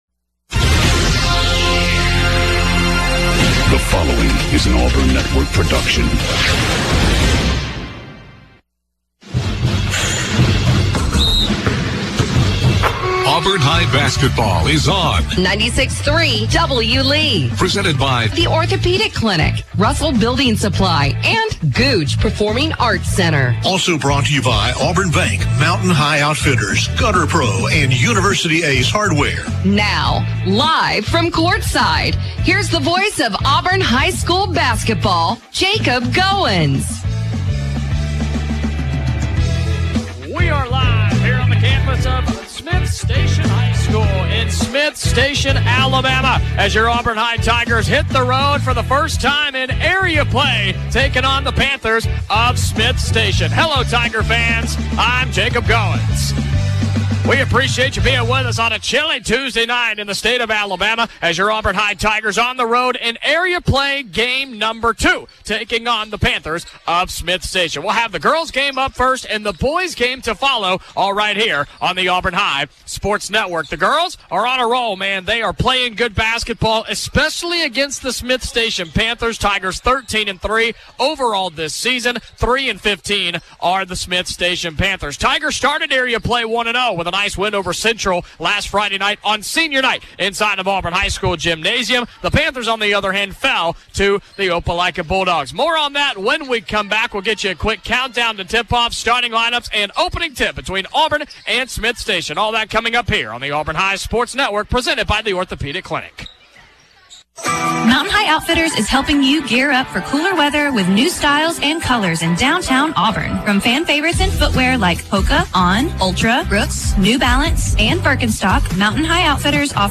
call Auburn High's game against Smiths Station. The Tigers won 68-26.